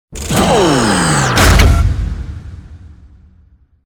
bladesoff.ogg